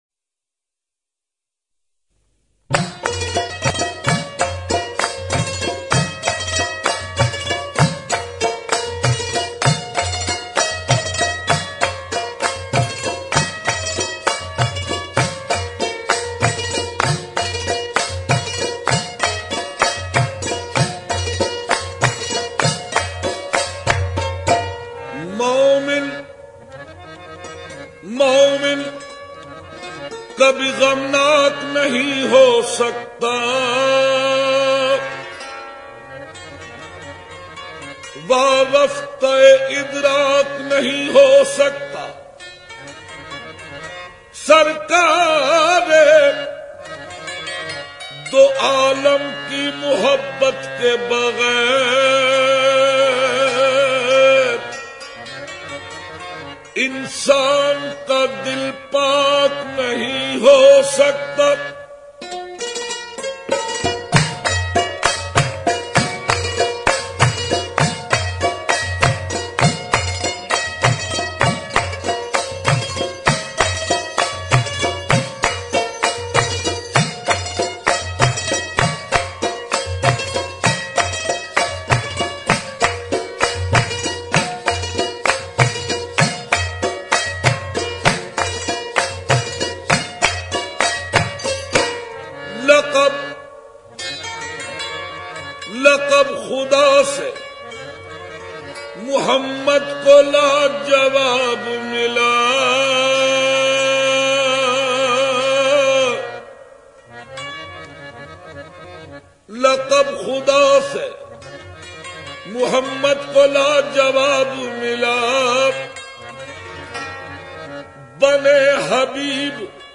Islamic Qawwalies And Naats